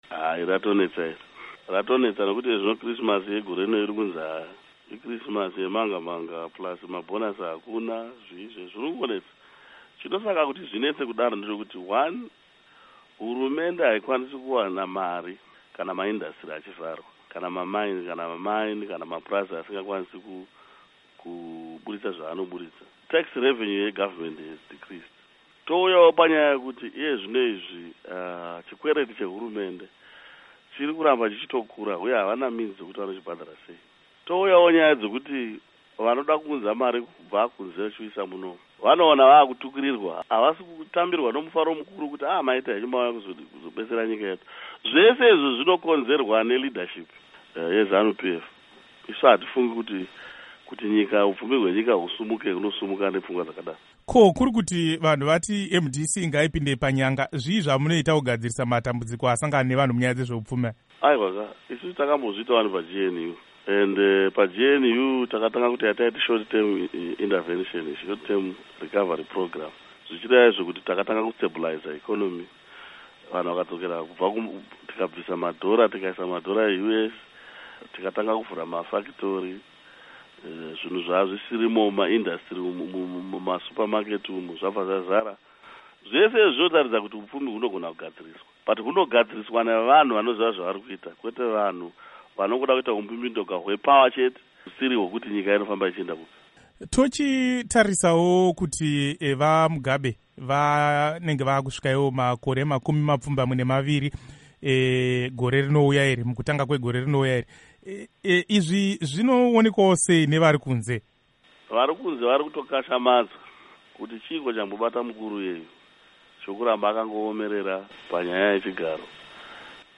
Interview with MDC-T Leader Morgan Tsvangirai